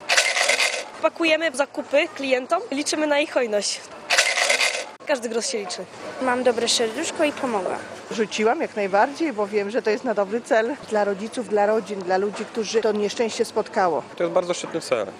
Komentarz audio (60Kb)